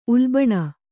♪ ulbaṇa